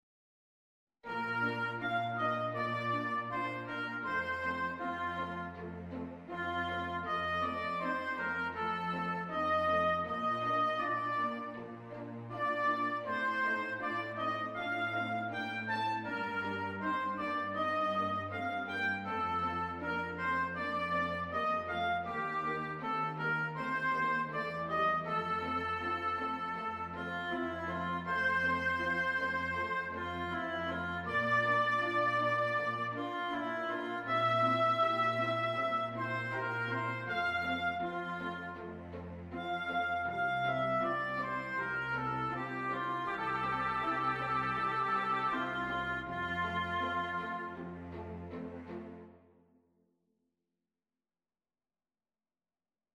Performance excerpts
Oboe and Strings